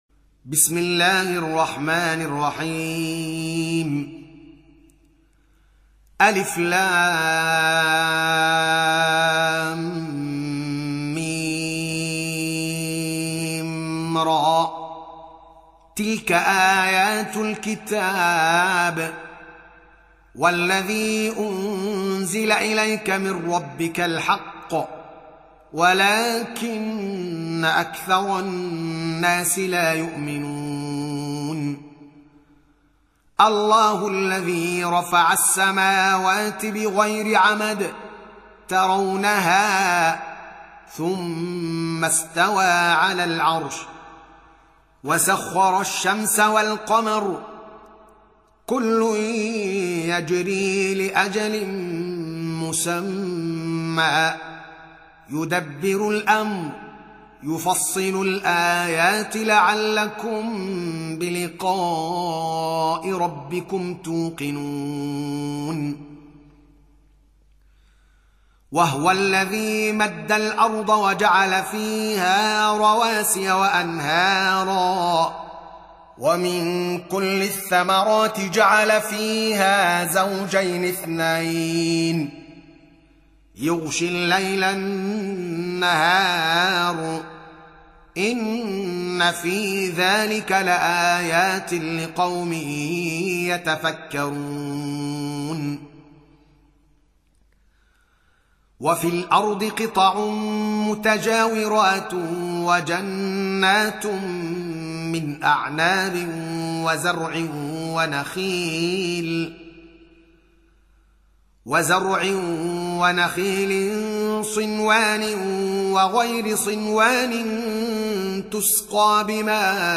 13. Surah Ar-Ra'd سورة الرعد Audio Quran Tarteel Recitation
Surah Sequence تتابع السورة Download Surah حمّل السورة Reciting Murattalah Audio for 13. Surah Ar-Ra'd سورة الرعد N.B *Surah Includes Al-Basmalah Reciters Sequents تتابع التلاوات Reciters Repeats تكرار التلاوات